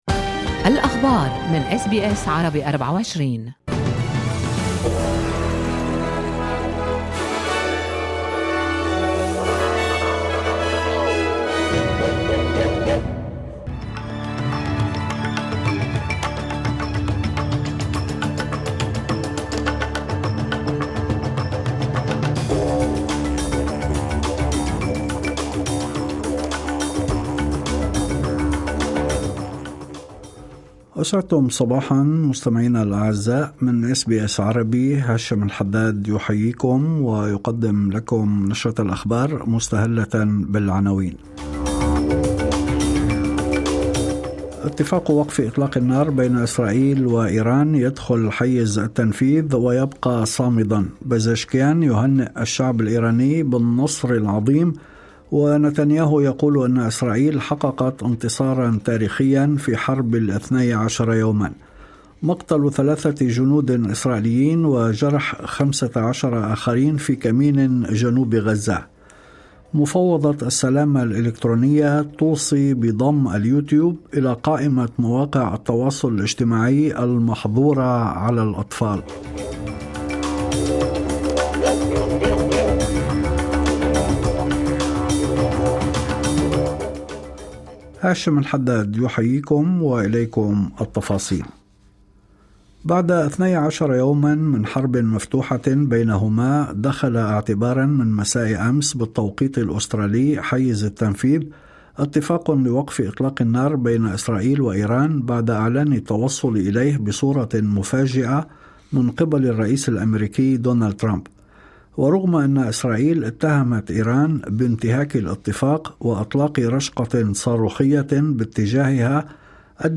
نشرة أخبار الصباح 25/06/2025